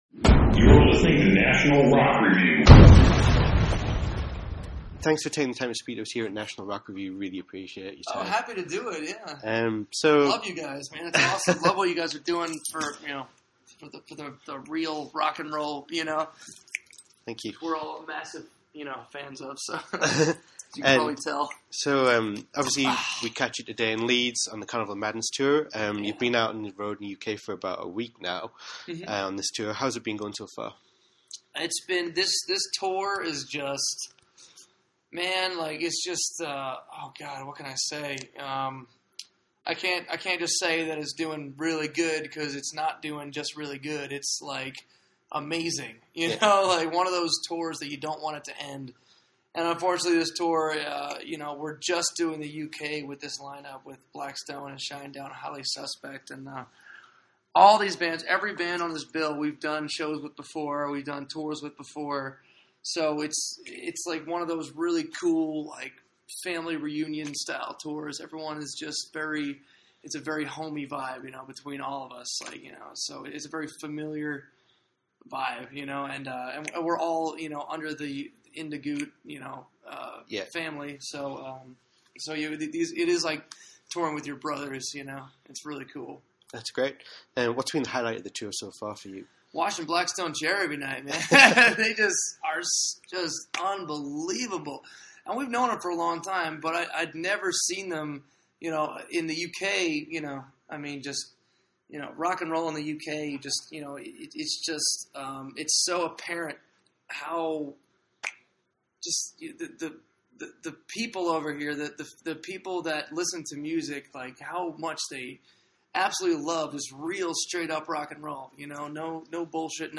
National Rock Review caught up with the band’s energetic drummer, Arejay Hale , at the Leeds date of the Carnival of Madness tour to talk about life on the road, the band’s touring plans for 2016, and their new video for “Mayhem.”